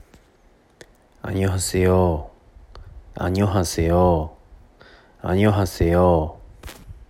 まずは、안녕하세요（アンニョンハセヨ）の発音からです。
【アンニョンハセヨの発音】